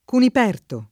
Cuniperto [ kunip $ rto ]